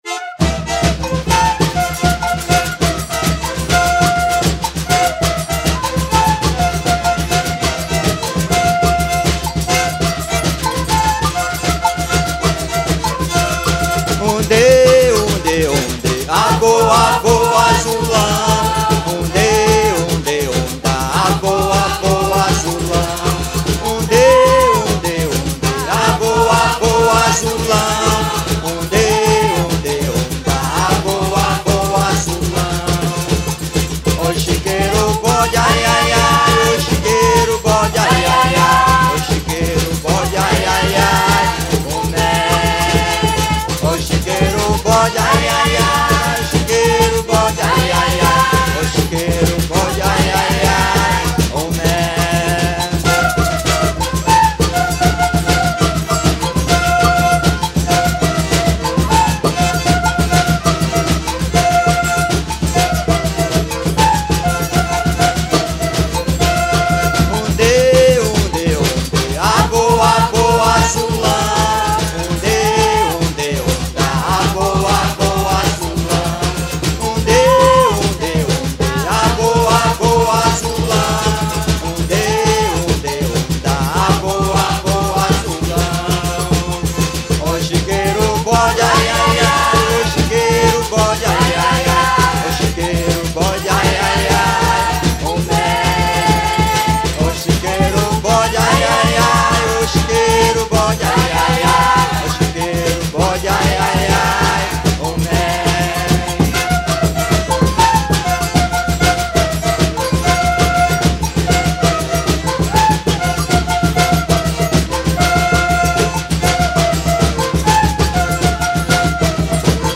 707   03:38:00   Faixa:     Folclore Brasileiro